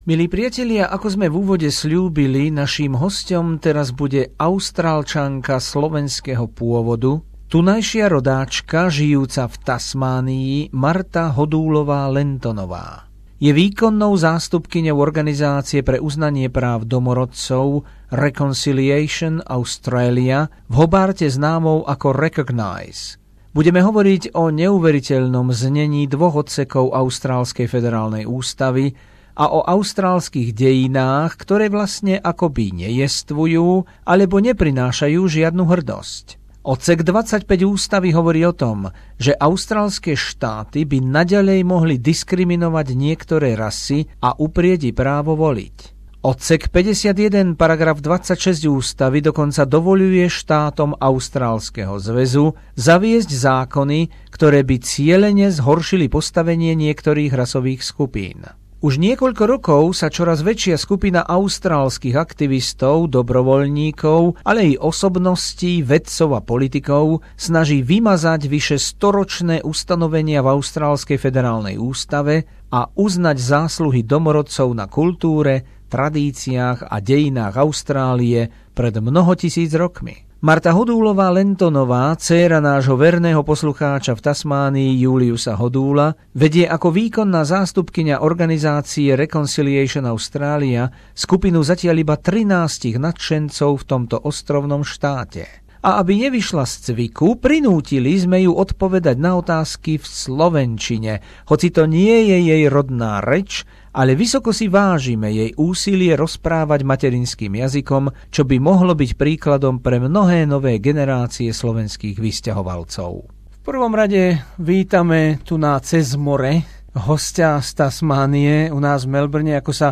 Rozhovor s dcérou slovenského prisťahovalca v Austrálii